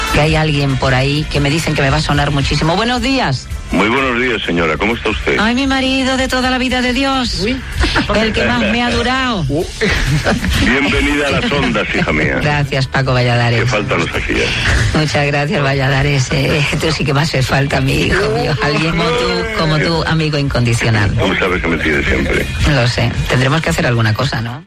Última hora del programa. Primera edició que Maria Teresa Campos presentava aquesta franja del programa. Fragment de la conversa amb l'actor Paco Valladares
Info-entreteniment